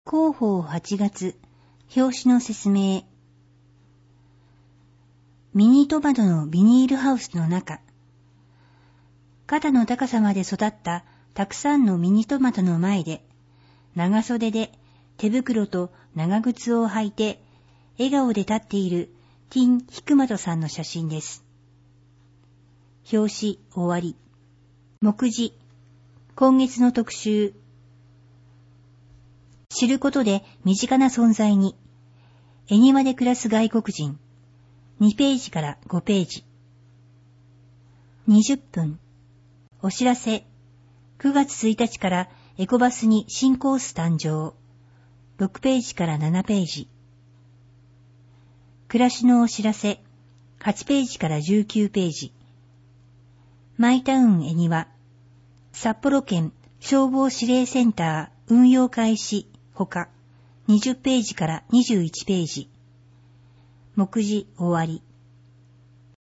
目の不自由な方向けに、恵庭市朗読赤十字奉仕団の協力により、広報えにわを抜粋して音声化した「声の広報」を作成しています。